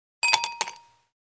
Кубики льда кинули в пустой стакан